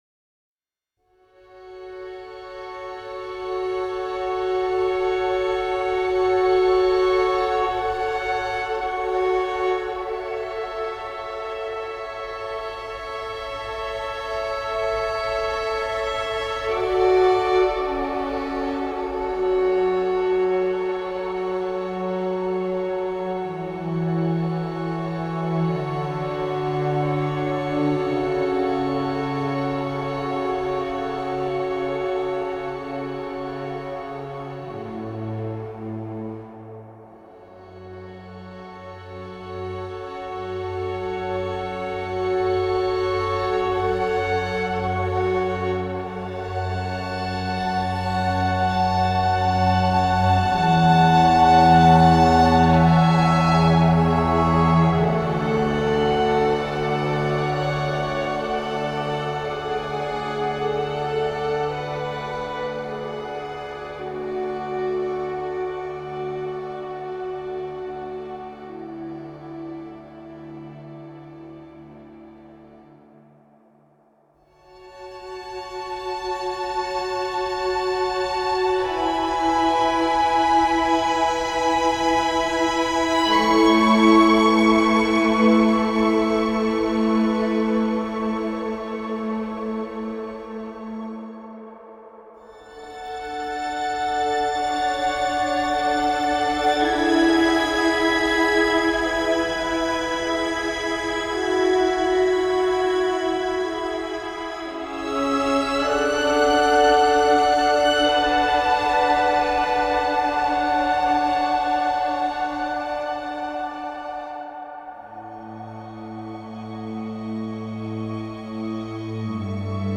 Ici plus apaisé, le score n’en est pas plus passionnant.
savant équilibre entre éléments électronique et acoustiques
À la fois simple et sophistiqué.